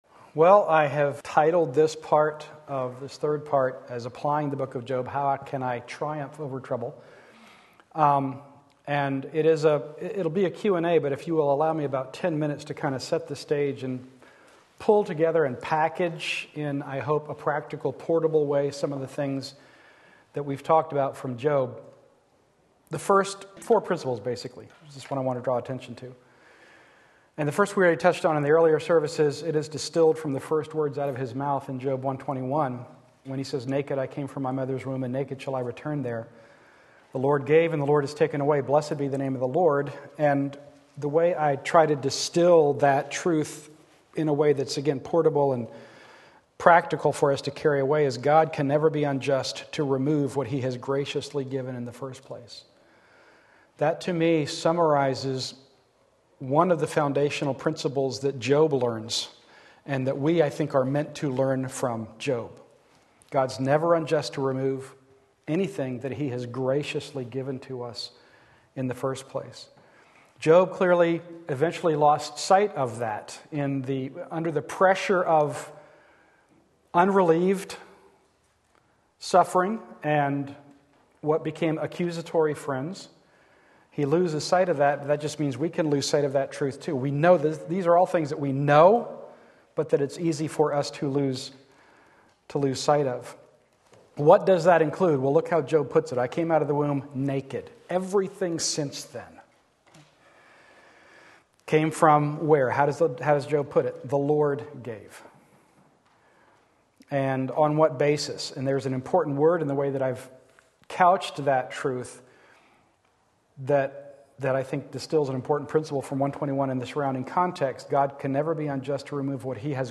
Sermon Link
Sunday Afternoon Service